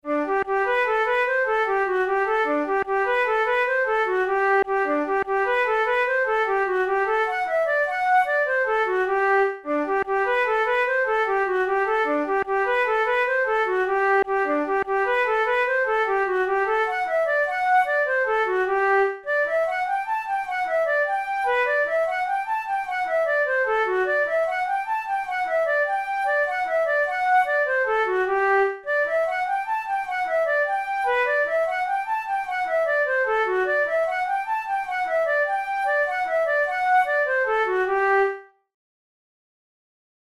Traditional Irish jig
Categories: Jigs Traditional/Folk Difficulty: easy